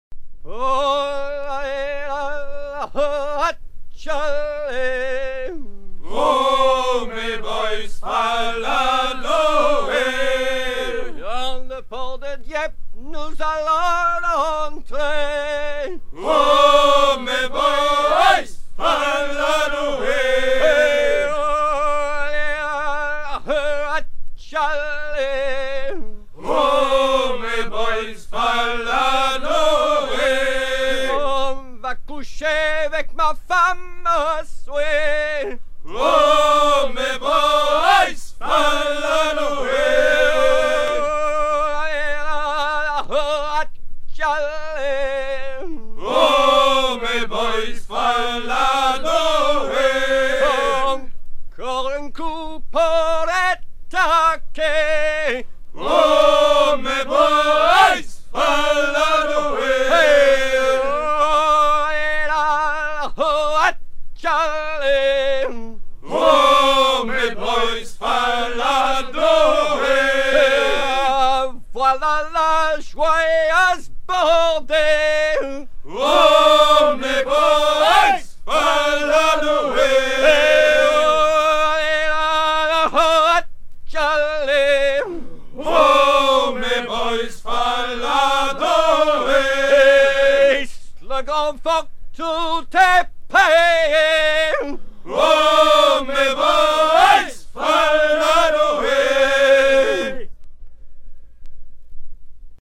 chants brefs
Pièce musicale éditée